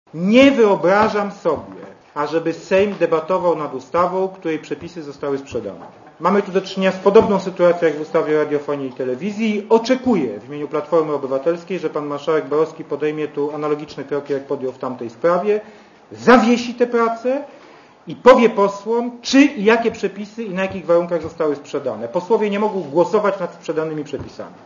Posłuchaj Jana Rokity w Radiu Zet (96Kb)